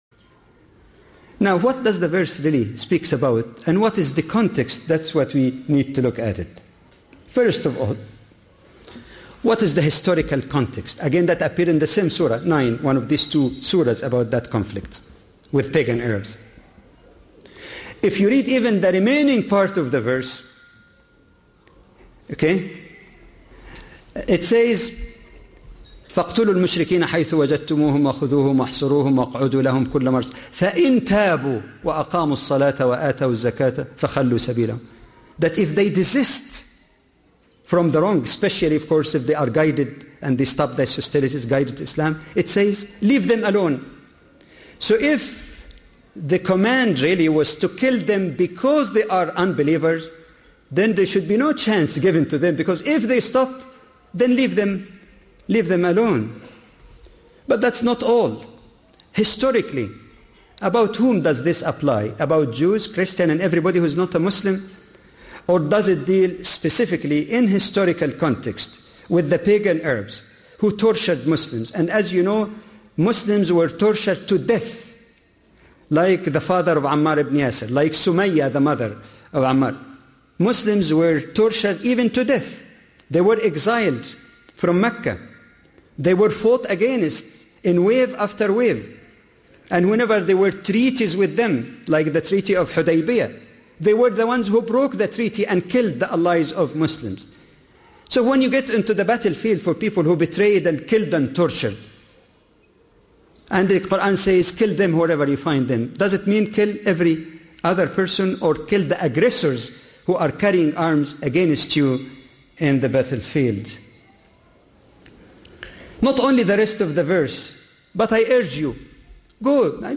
Muslim/Non-Muslim Relations: Commonly Misunderstood Qur’anic Texts – A lecture